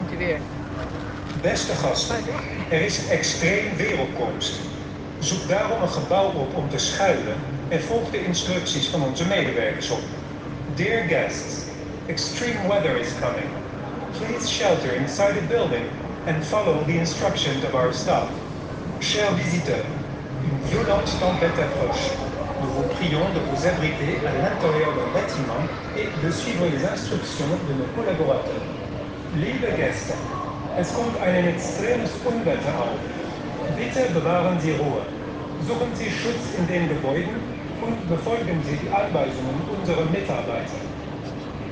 Audio: Efteling: waarschuwing extreem weer (fragment)